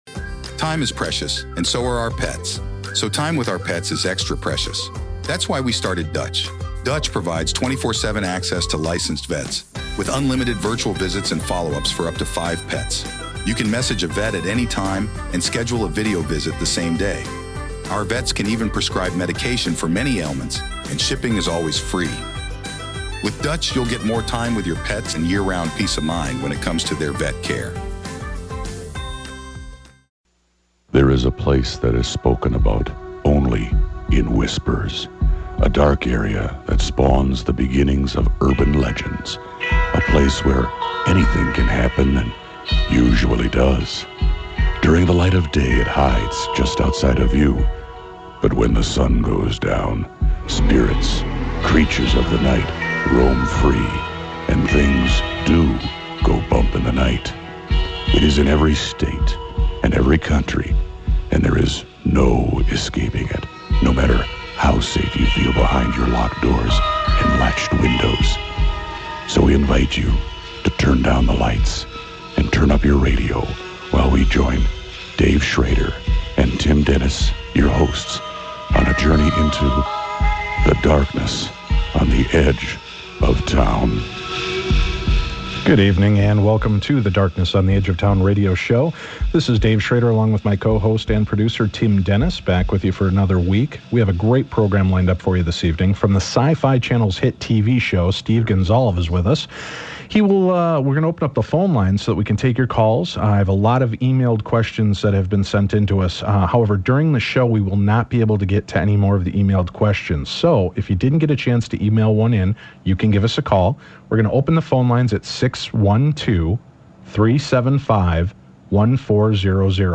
Steve talks about his different investigating techniques, his favorite cases, and takes questions from our audience!